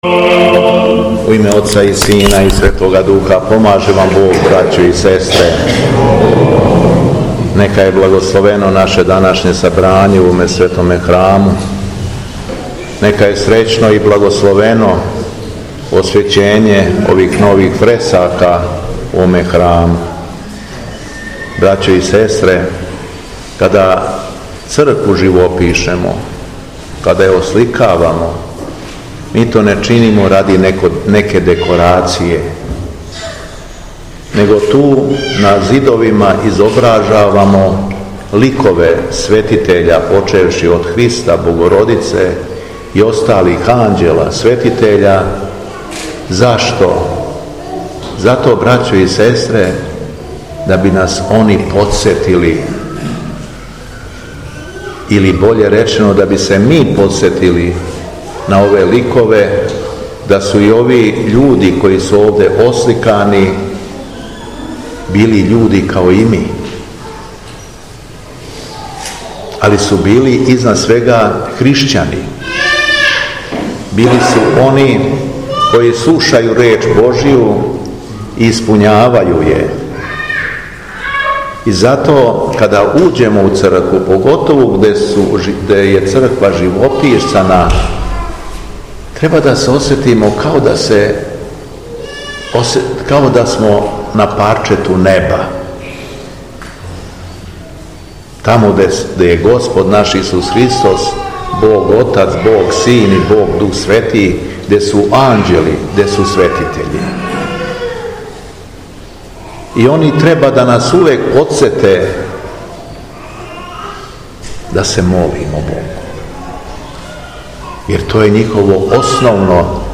АРХИЈЕРЕЈСКА БЕСЕДА – МИТРОПОЛИТ ШУМАДИЈСКИ Г. ЈОВАН: Улазак у Цркву је као улазак на небо
У недељу 31. августа 2025. године, када Црква прославља Свете мученике Флора и Лавра и Преподобног Јована Рилског, Његово Високопреосвештенство Митрополит шумадијски Господин Јован, служио је архијерејску Литургију у храму Светог Саве на Липару у колубарско-посавском намесништву.